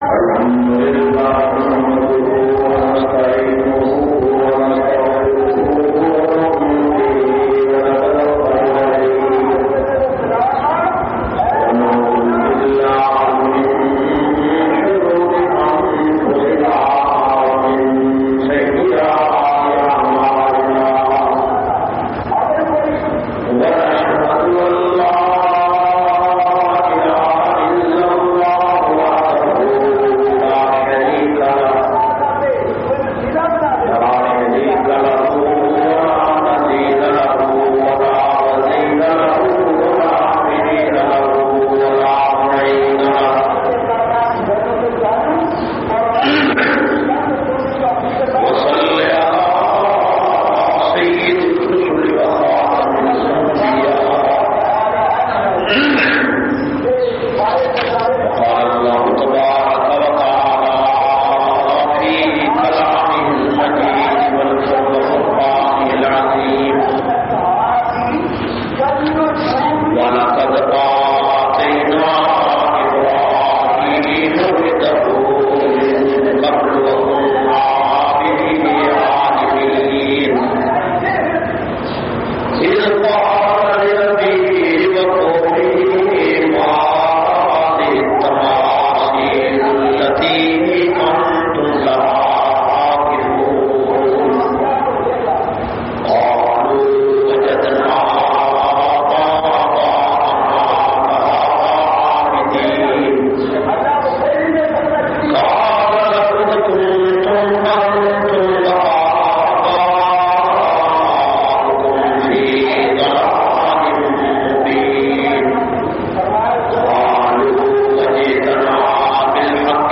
492- Hazrat Ibraheem A.S khutba Jumma Jamia Masjid Muhammadia Samandri Faisalabad.mp3